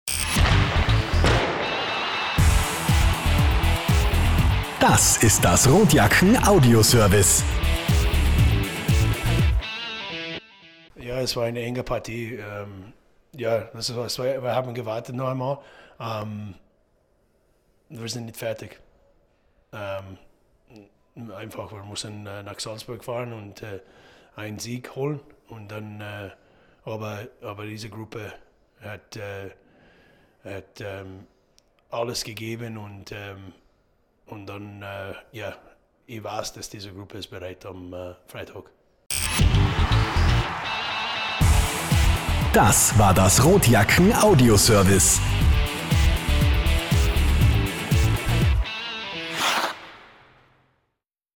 Post Game (F #3)